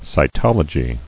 [si·tol·o·gy]